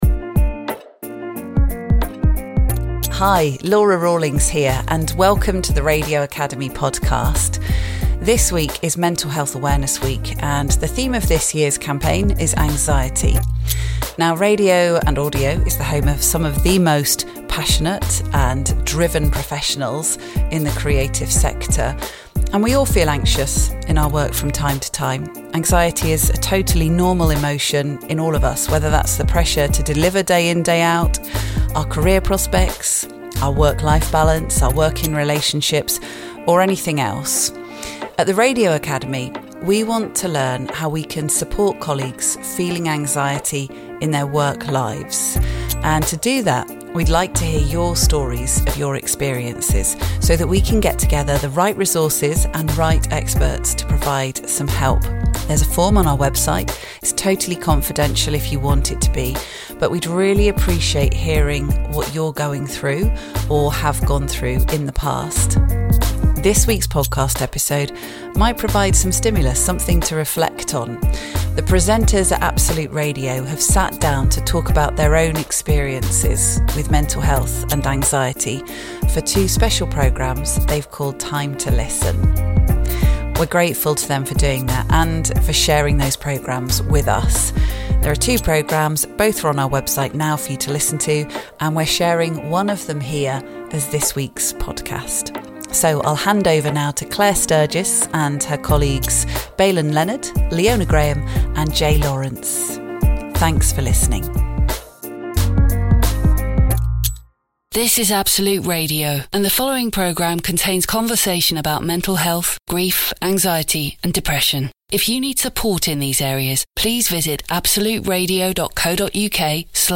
The presenters at Absolute Radio have sat down to talk about their own experiences with mental health and anxiety, for two special programmes they've called Time To Listen.